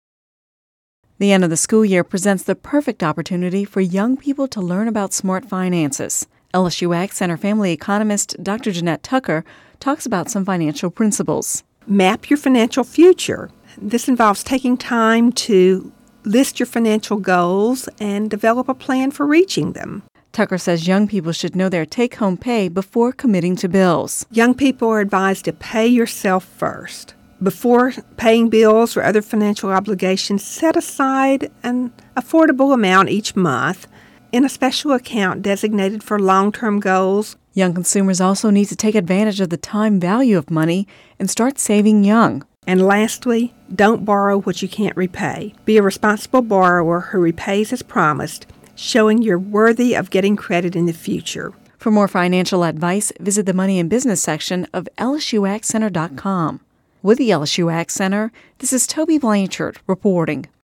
(Radio News 05/31/10) The end of the school year presents the perfect opportunity for young people to learn about smart finances.